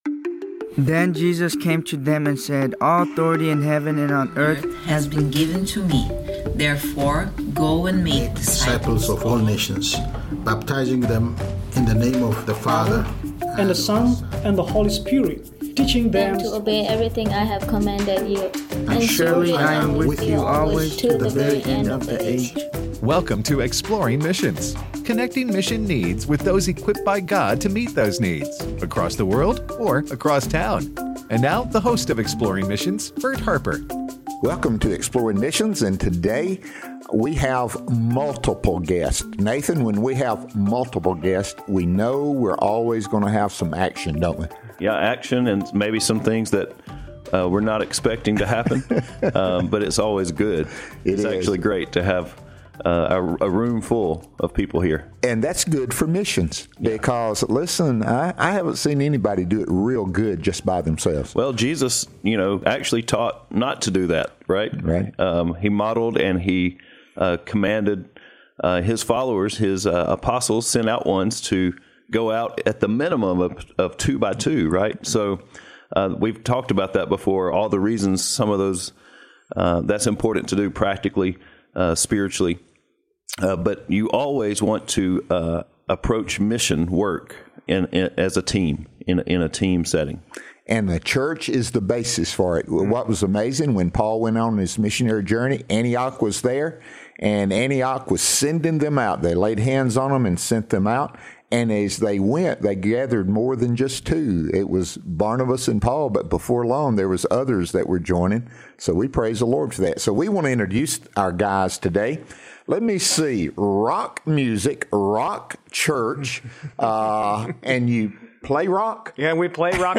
The Rock Music: A Conversation